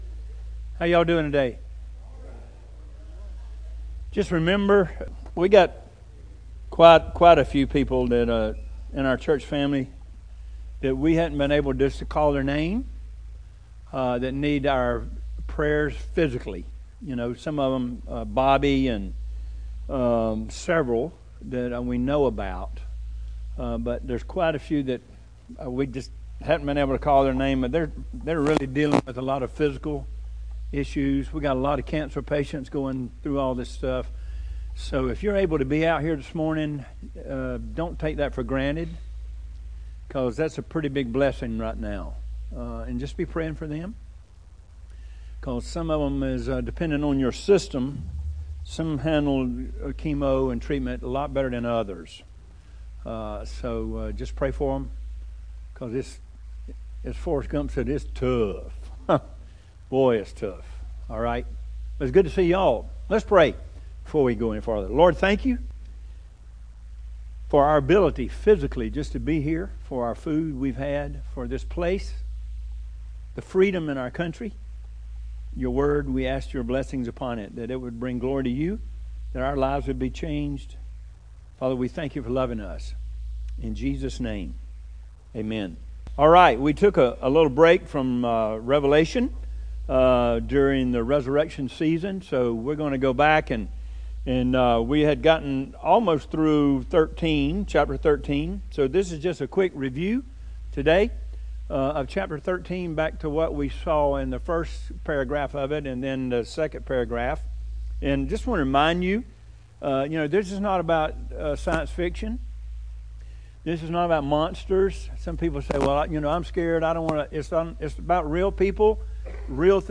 Weekly sermons from Harmony Community Church in Byron, Georgia.
0427Sermon.mp3